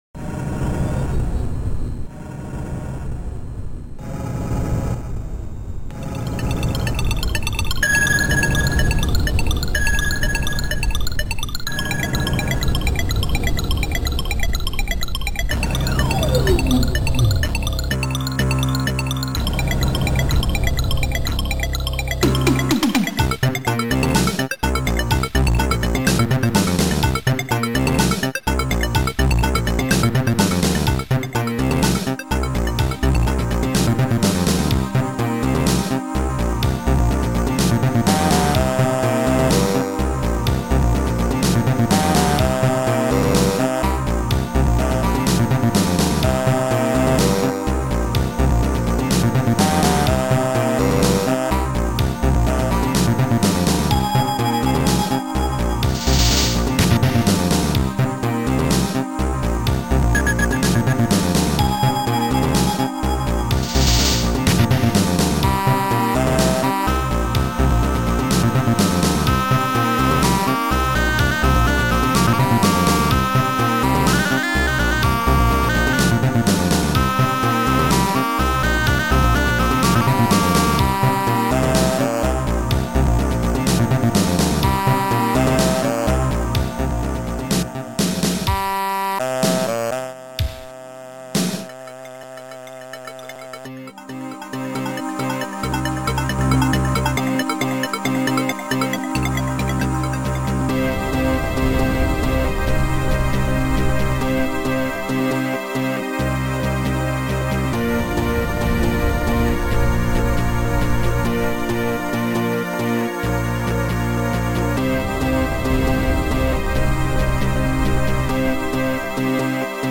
Sound Format: Noisetracker/Protracker
Sound Style: Disco Pop